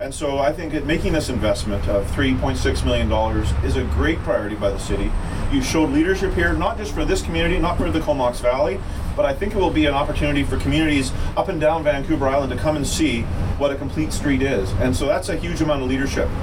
Comox Valley MLA Don McRae was on hand at city hall as well, along with a number of councilors.